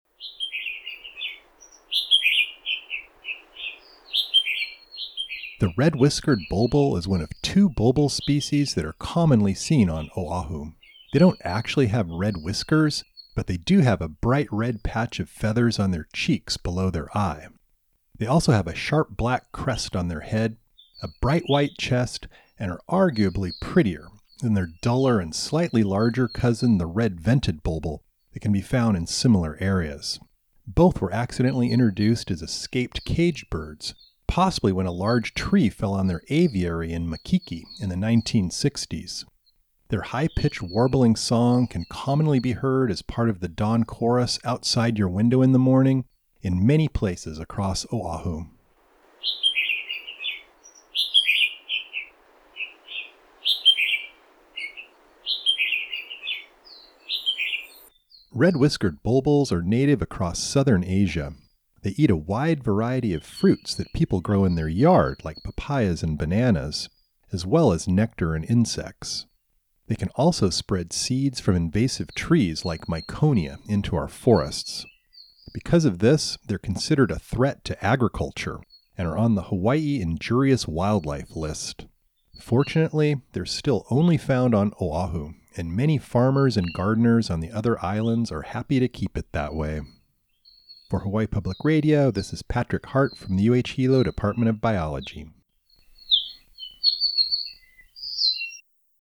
whiskeredbulbul.mp3